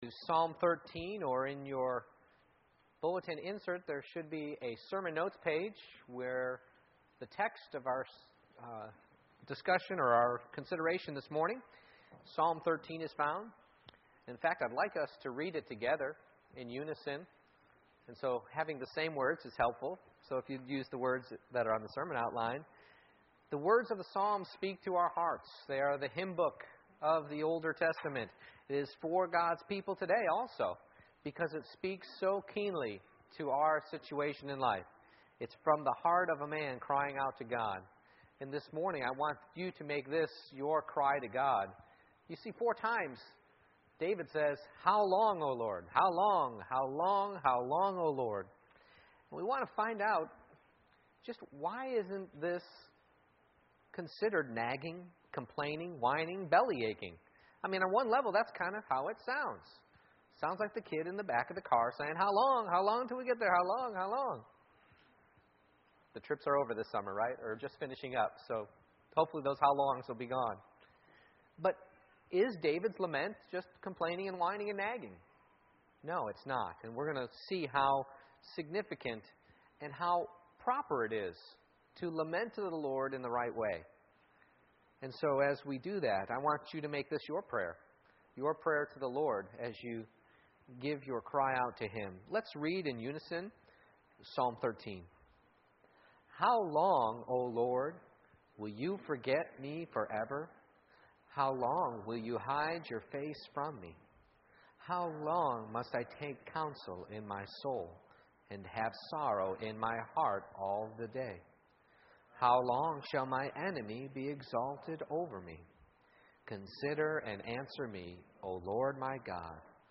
Exposition of the Psalms Passage: Psalm 13:1-6 Service Type: Morning Worship What Makes Us Groan "How Long"?